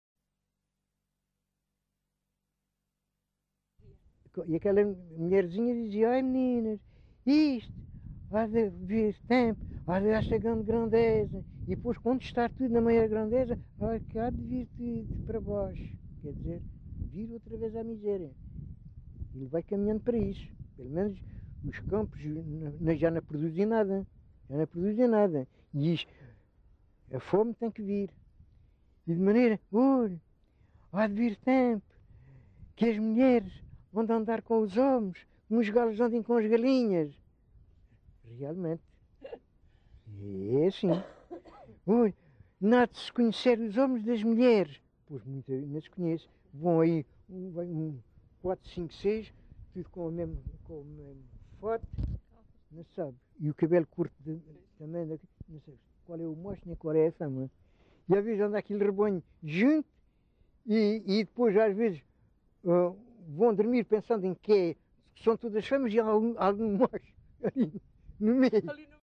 LocalidadePorches (Lagoa, Faro)